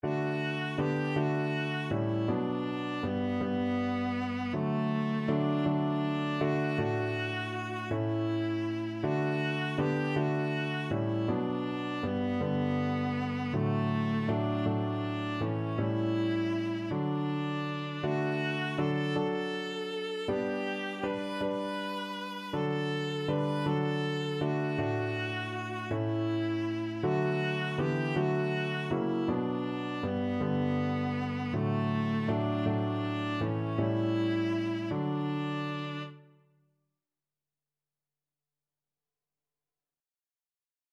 6/4 (View more 6/4 Music)
Classical (View more Classical Viola Music)